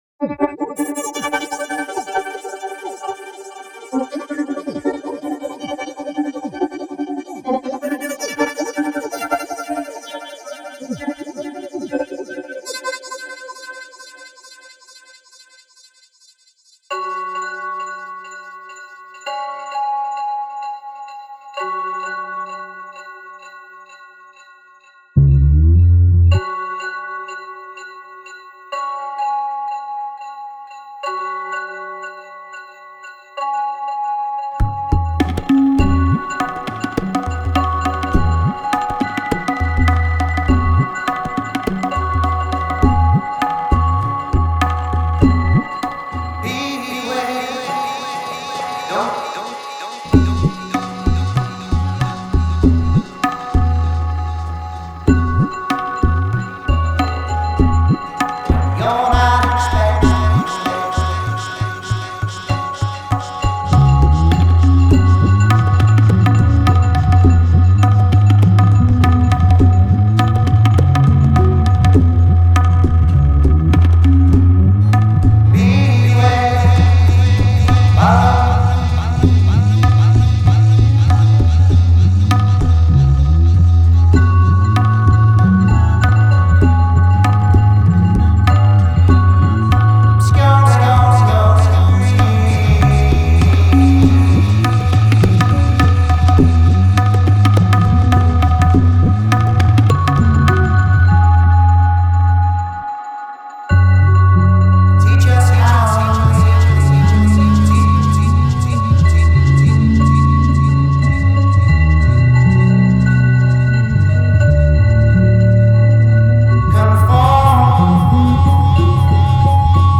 tabla me banana!!